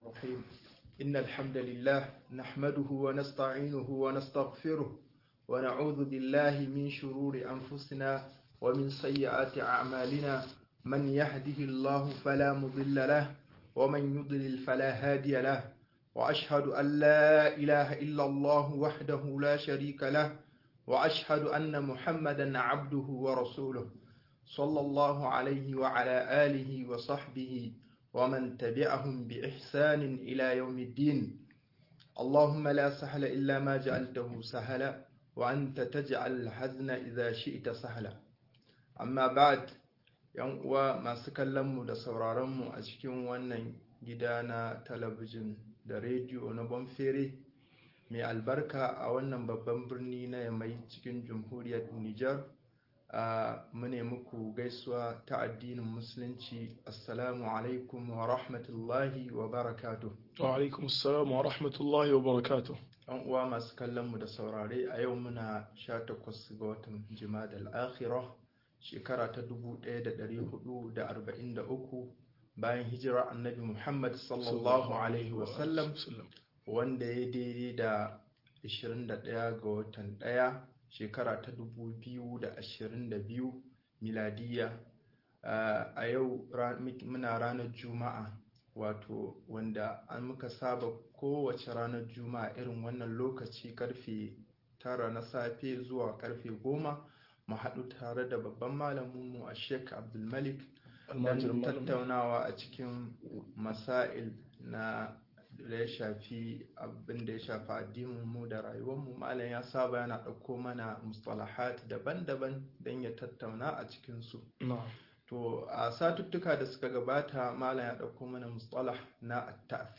Yin tasiri da tasirantuwa-03 - MUHADARA